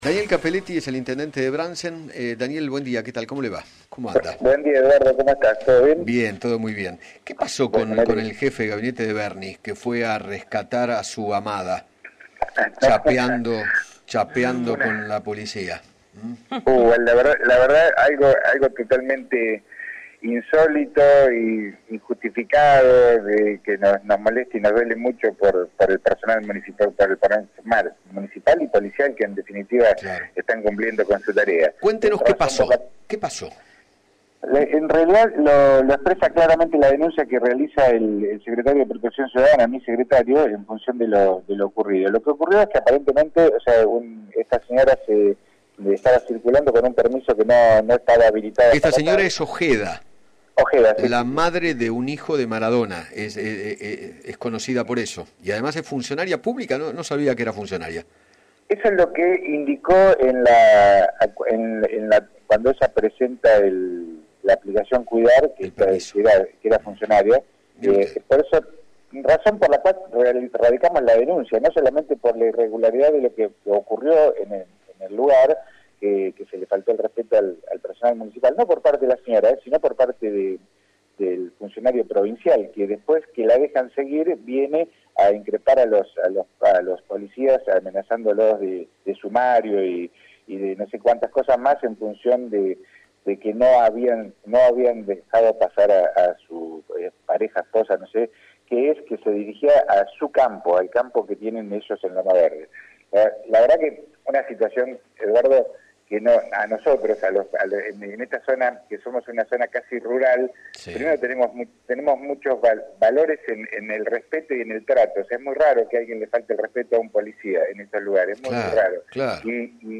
Daniel Cappelletti, Intendente de Brandsen, dialogó con Eduardo Feinmann sobre la denuncia que recibió la ex de Diego Maradona y su actual pareja, el jefe de Gabinete de Sergio Berni, Mario Baudry, por violar la cuarentena y agredir al personal policial.